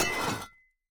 Minecraft Version Minecraft Version snapshot Latest Release | Latest Snapshot snapshot / assets / minecraft / sounds / item / axe / scrape3.ogg Compare With Compare With Latest Release | Latest Snapshot
scrape3.ogg